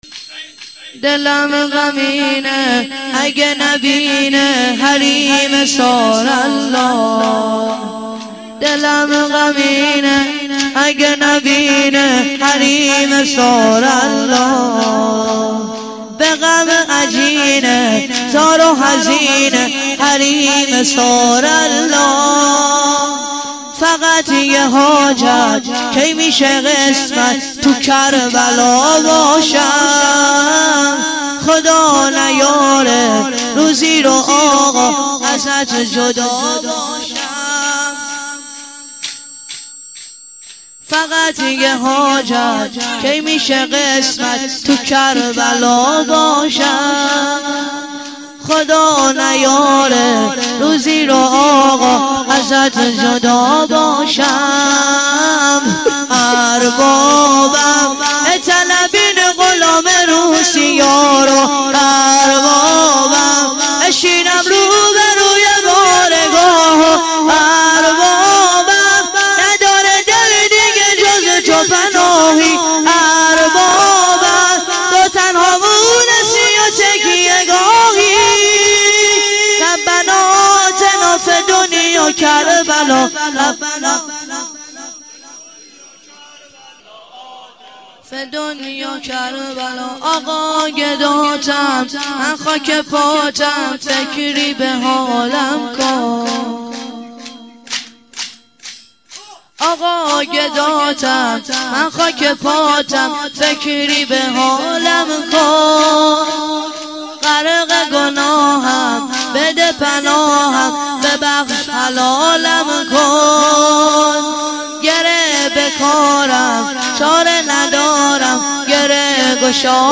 چهارضرب - دلم غمینه اگه نبینه حریم ثارالله - مداح